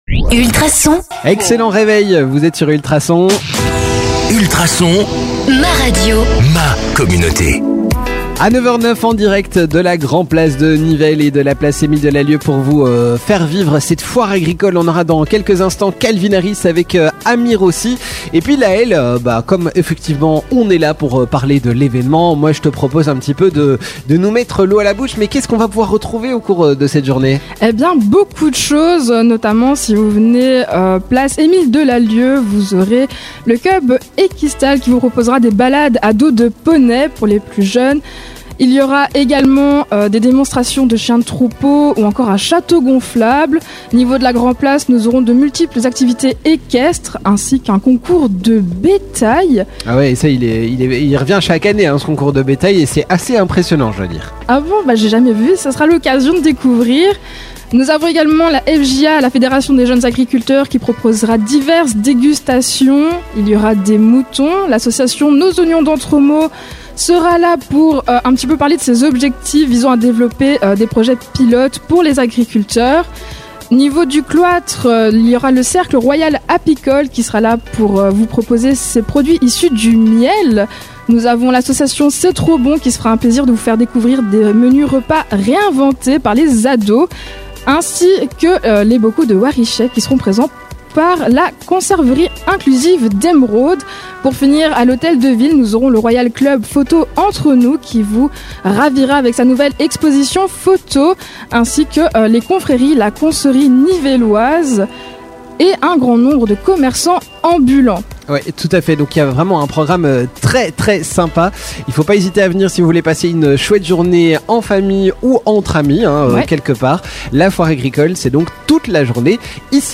vous ont fait vivre en direct la Foire Agricole de Nivelles 2024